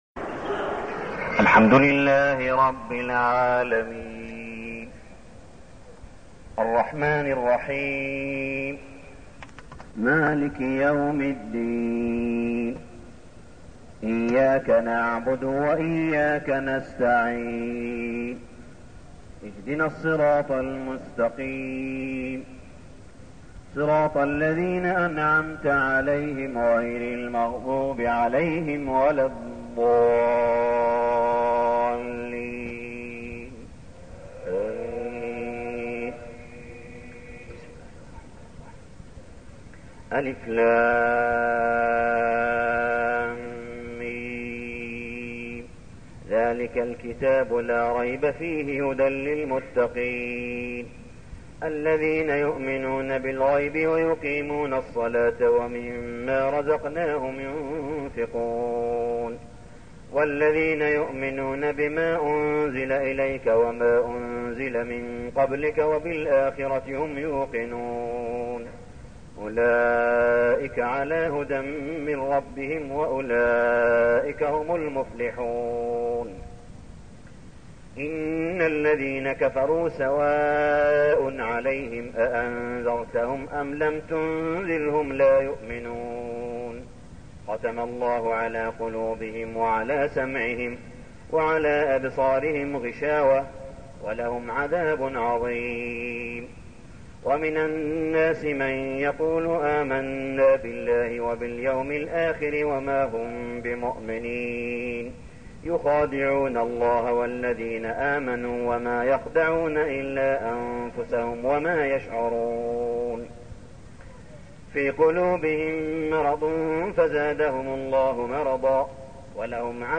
صلاة التراويح ليلة 1-9-1413هـ | سورة البقرة 1-74 | > تراويح الحرم المكي عام 1413 🕋 > التراويح - تلاوات الحرمين